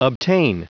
Prononciation du mot : obtain
obtain.wav